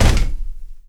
FEETS 1   -R.wav